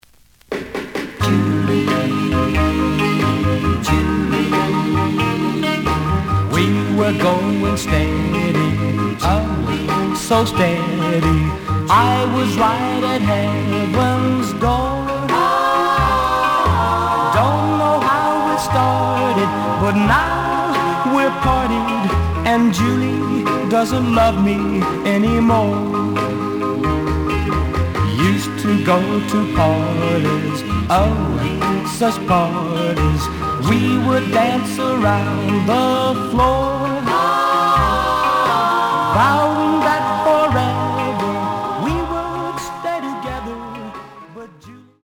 試聴は実際のレコードから録音しています。
●Genre: Rhythm And Blues / Rock 'n' Roll
●Record Grading: VG+ (盤に若干の歪み。多少の傷はあるが、おおむね良好。)